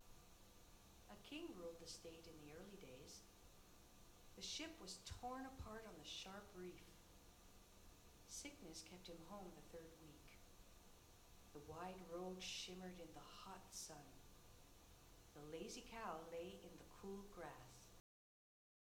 How much the headphones attenuate the voice of a person talking to you when background noise is present.
Female Voice 1
female-voice-1-sample.wav